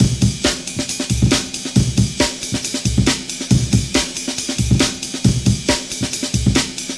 • 69 Bpm Breakbeat Sample C Key.wav
Free drum groove - kick tuned to the C note.
69-bpm-breakbeat-sample-c-key-6Pj.wav